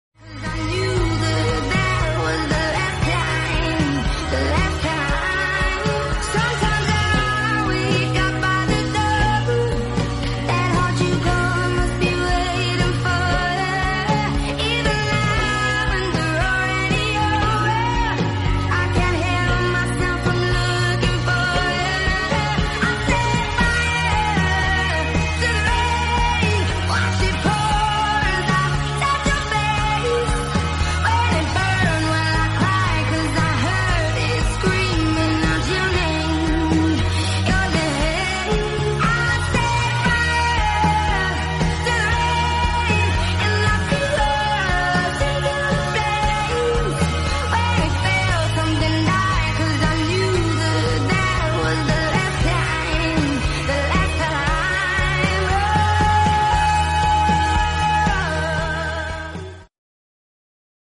*last ever* Dublin bus SG115 sound effects free download
*last ever* Dublin bus SG115 is seen at foxrock church on the last ever 84x operation 24/1/2025